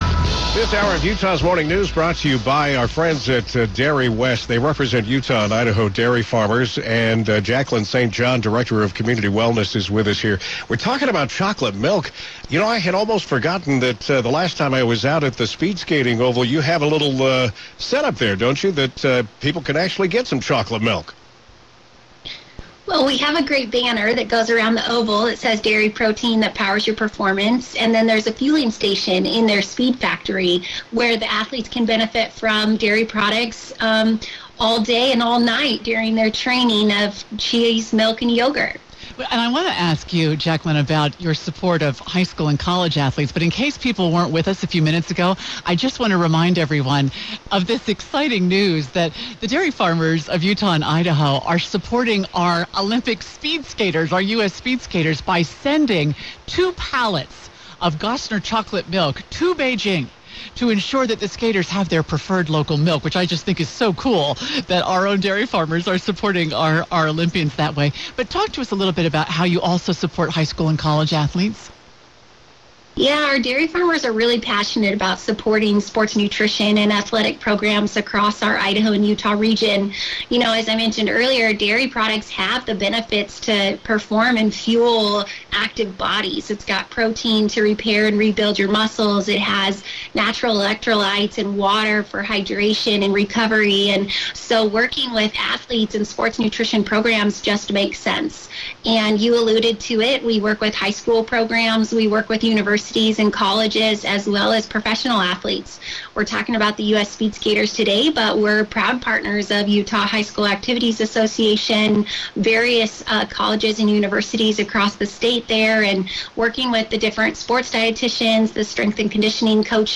KSL-TV, KSL Radio, the NBC Olympics streaming service, and Team USA have all featured the partnership in news stories and live segments.